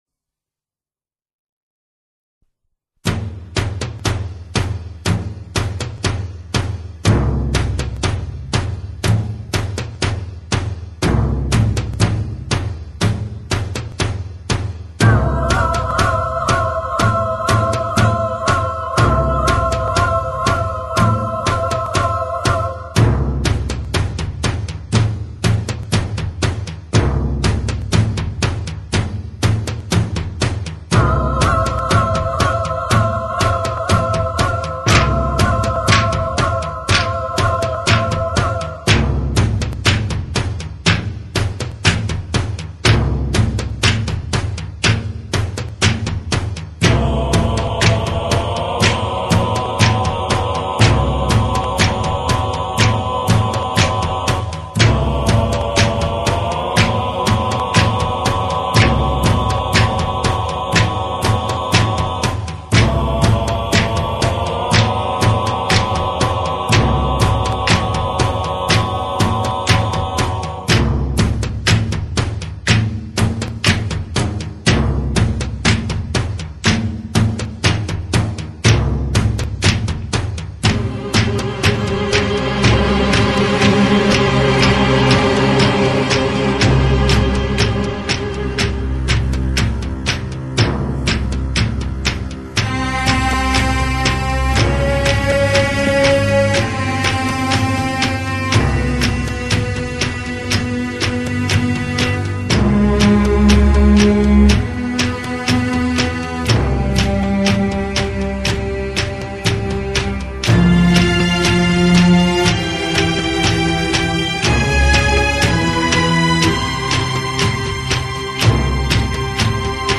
موسیقی متن آثار تصویری
موسیقی مذهبی و آیینی